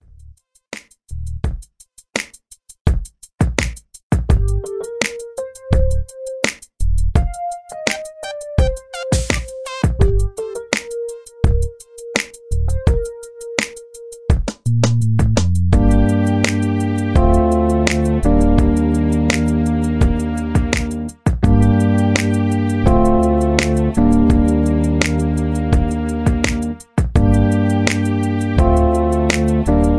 R&B Balad